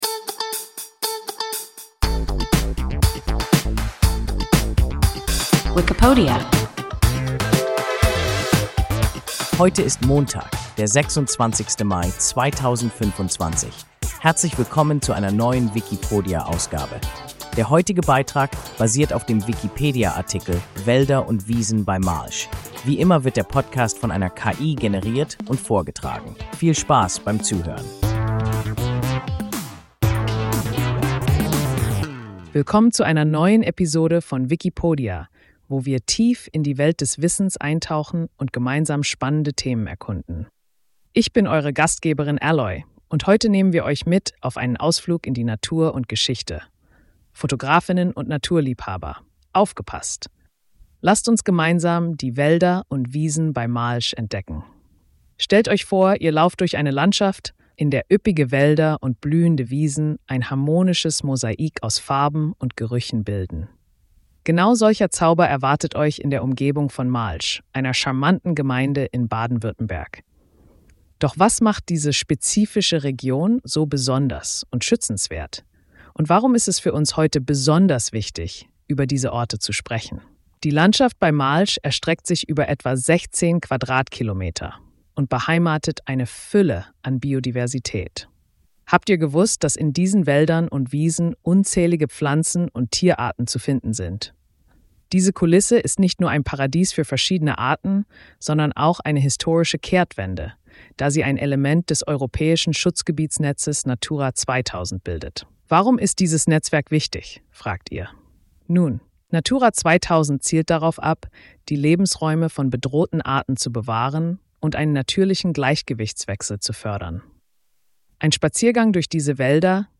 Wälder und Wiesen bei Malsch – WIKIPODIA – ein KI Podcast